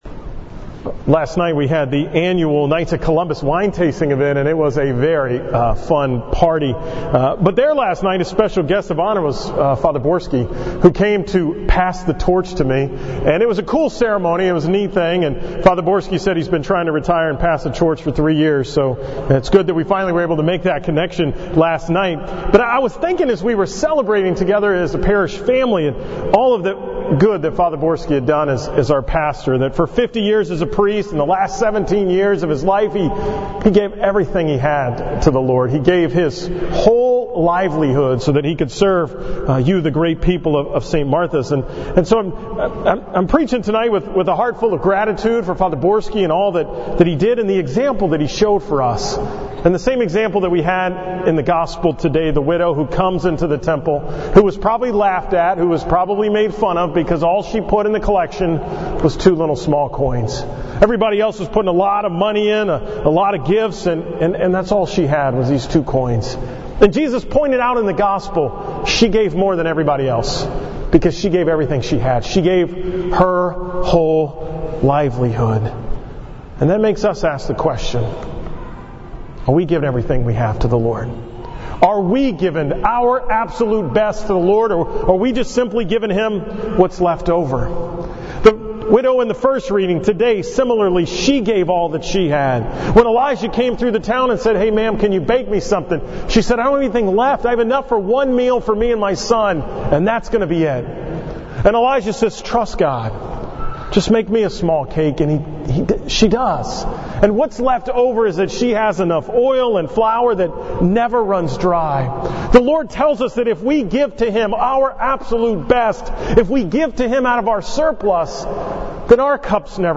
From the 5:30 pm Mass at St. Martha's on November 11, 2018